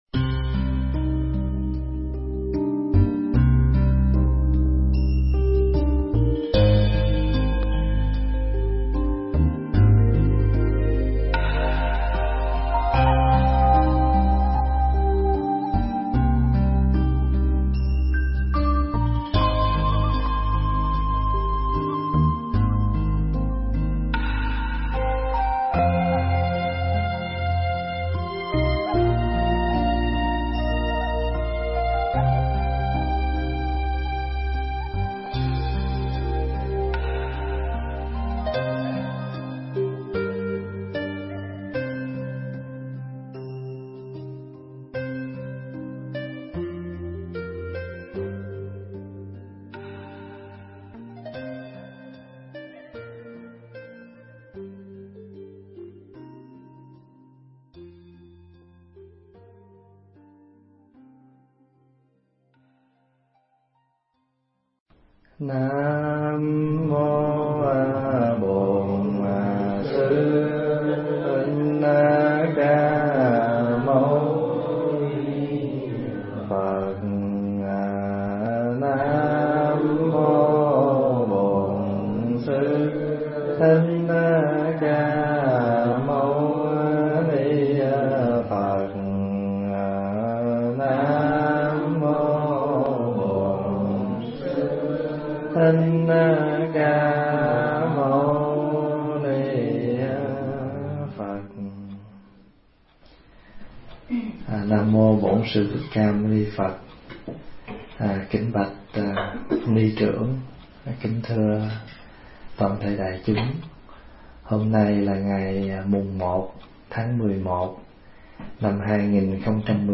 thuyết giảng tại Chùa Linh Sơn